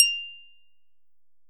ギャグ・アニメ調（変な音）
ピーン！/閃き３